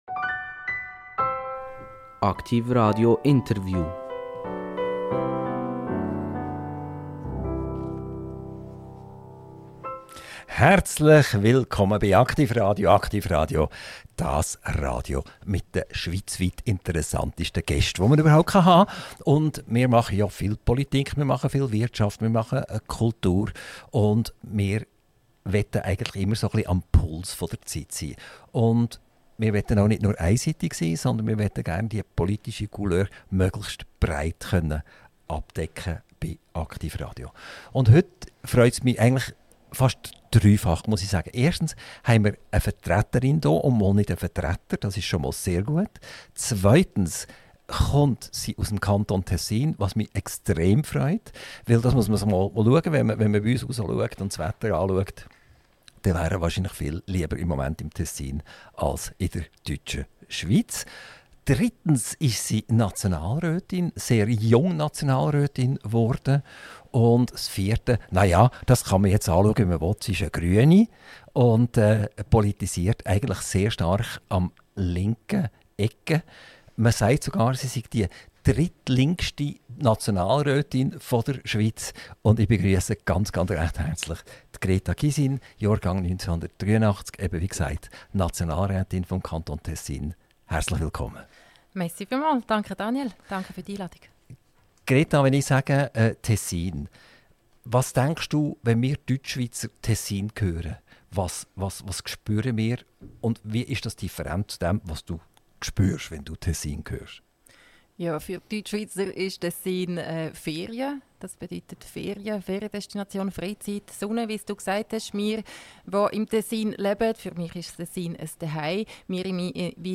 INTERVIEW - Greta Gysin - 25.11.2025 ~ AKTIV RADIO Podcast